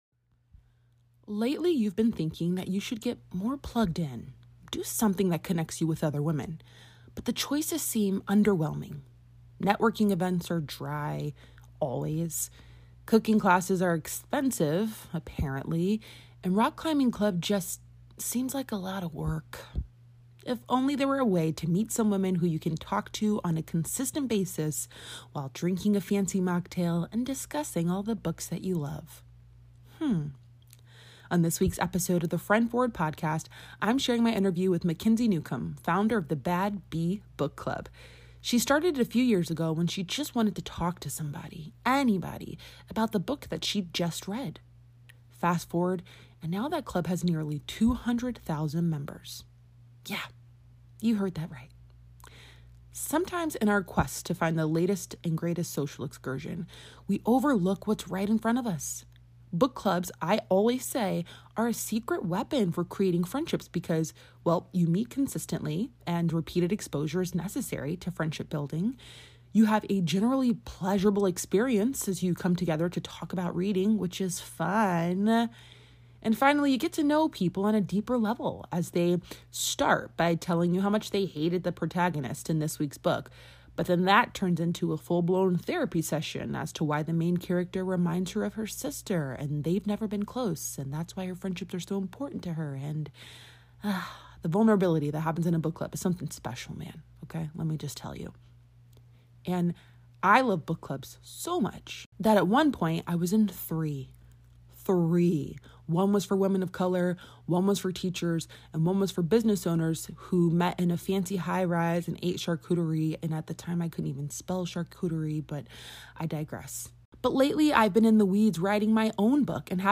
**Book clubs aren't anything new, but they're a tried and true strategy for finding new friends and nurturing relationships with them over time. Today I'm interviewing